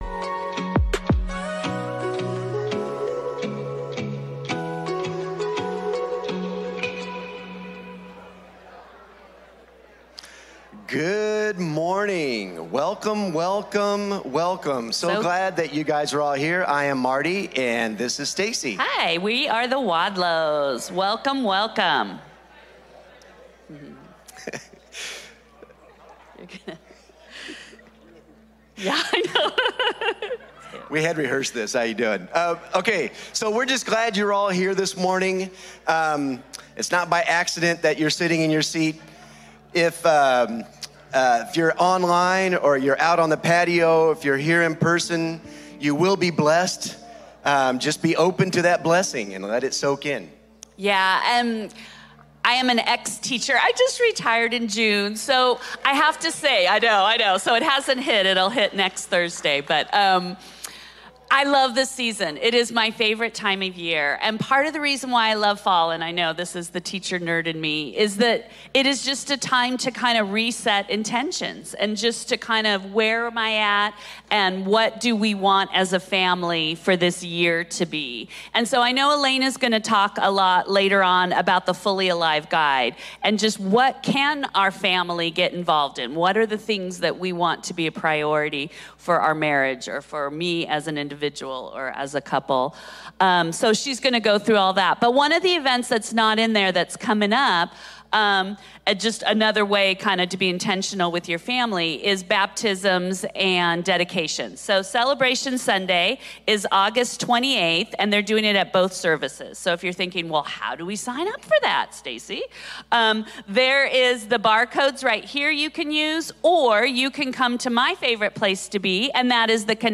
A message from the series "Prison Letters."